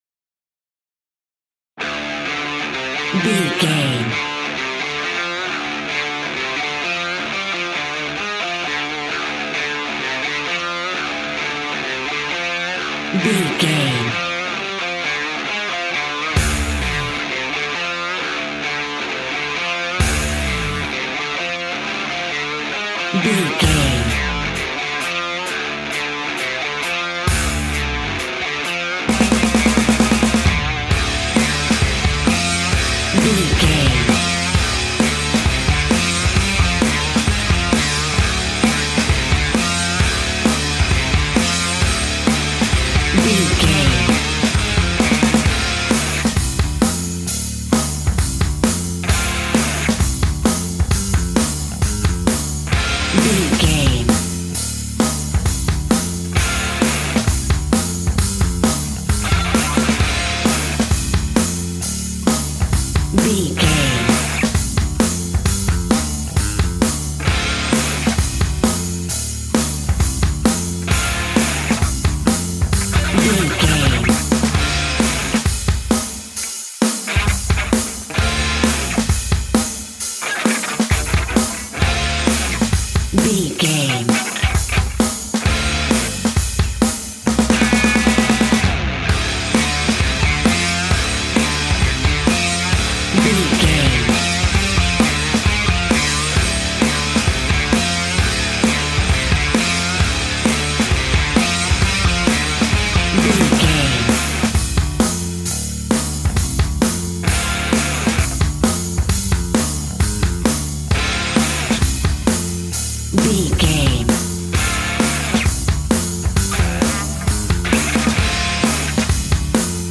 Aeolian/Minor
D
hard rock
heavy metal
instrumentals
Heavy Metal Guitars
Metal Drums
Heavy Bass Guitars